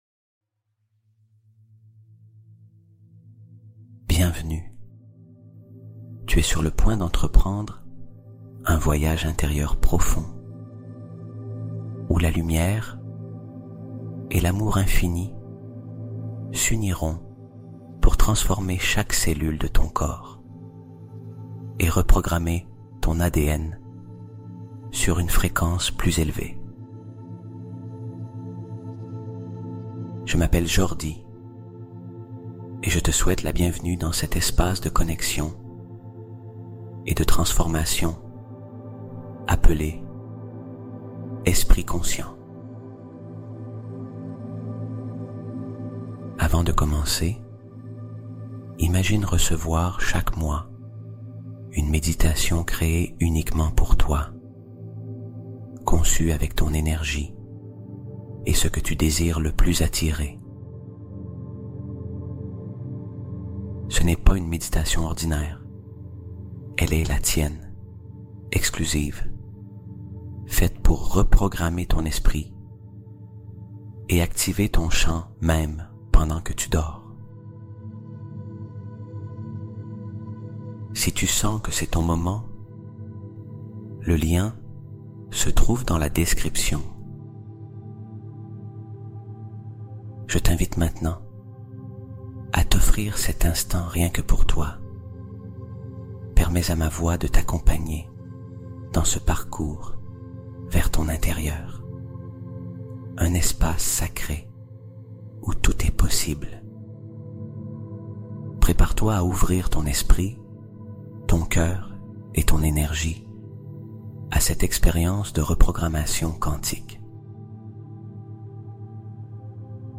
Harmonie Cellulaire : Méditation de relaxation profonde et de bienveillance